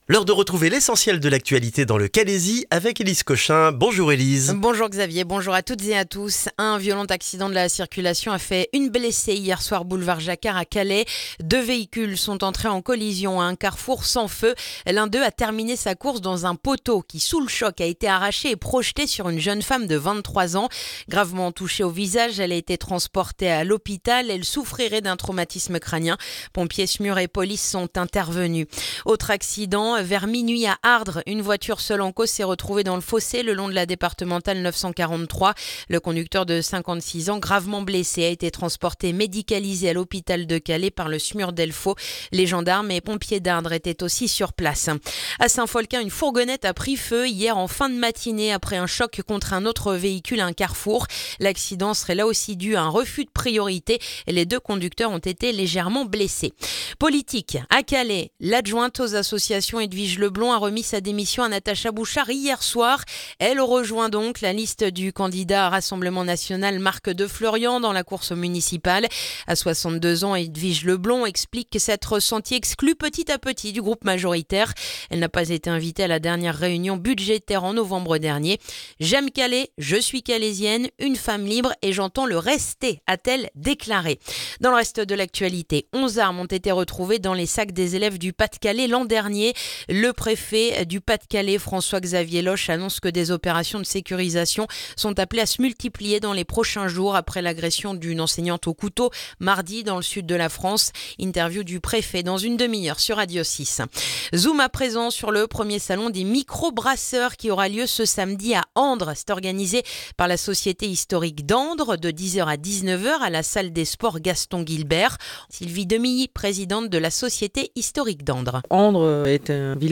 Le journal du jeudi 5 février dans le calaisis